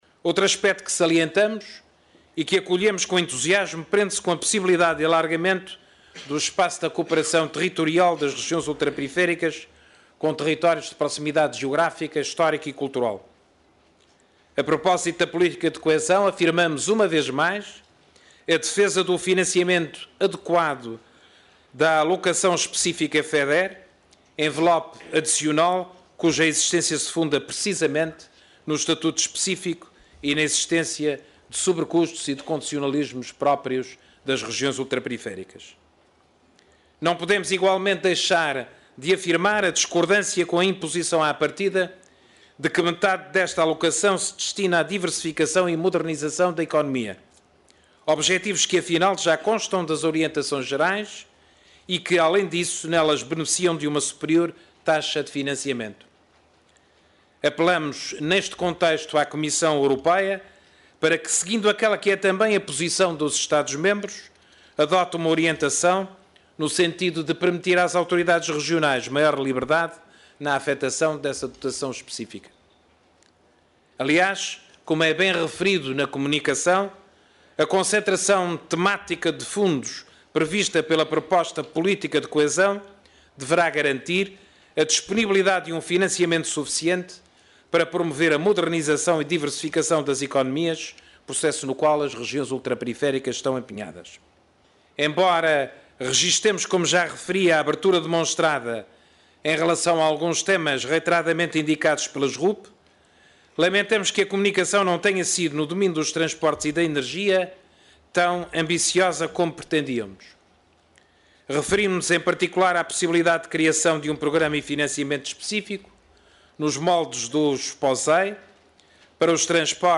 Carlos César falava na sessão de abertura do II Fórum das Regiões Ultraperiféricas da União Europeia – que contou com a presença do Presidente da Comissão europeia, Durão Barroso, e do Comissário de Política Regional, Johannes Hahn –, e fez notar também que, para se atingir um crescimento inteligente, sustentável e inclusivo para as RUP “é importante a consolidação de uma Europa mais unida, mais coesa e mais solidária, economicamente mais ativa e financeiramente mais sustentada, para todos termos sucesso neste caminho que queremos percorrer juntos rumo à Europa 2020”.